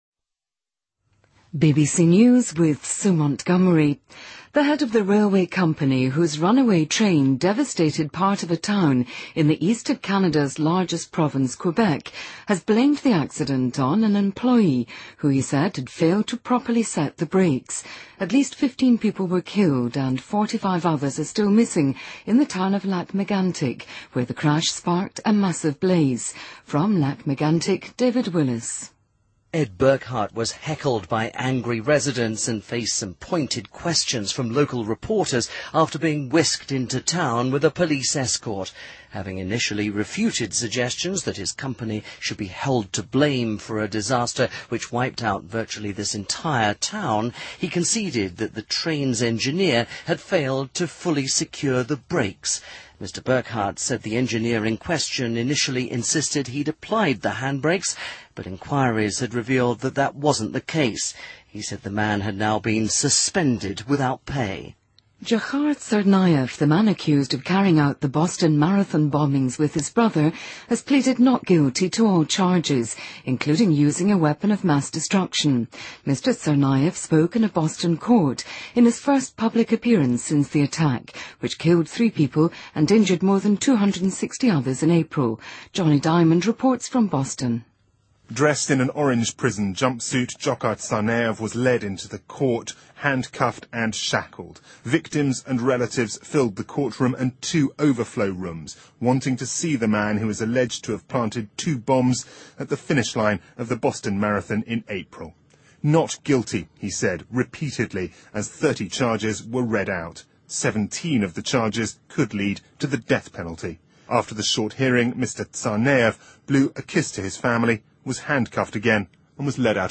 BBC news,美国大型零售连锁店宣布对孟加拉国服装工人实施更严格的安全标准